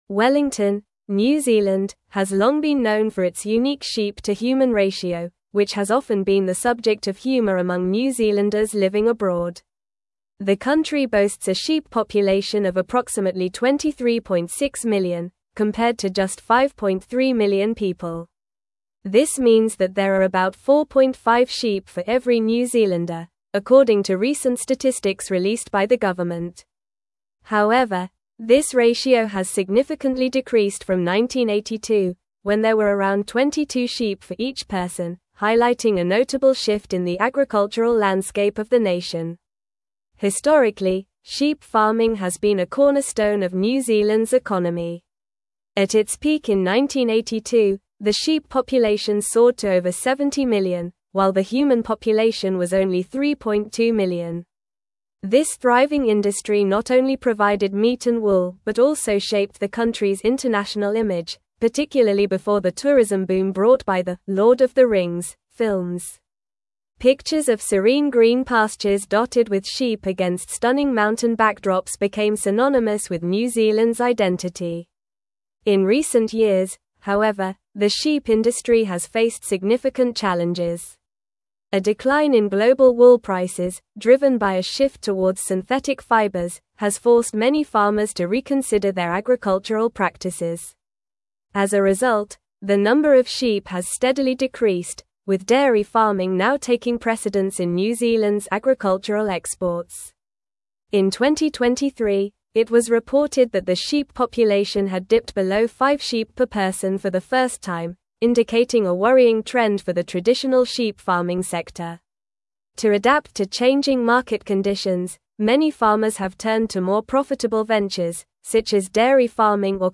English-Newsroom-Advanced-NORMAL-Reading-Decline-of-New-Zealands-Sheep-Population-and-Industry.mp3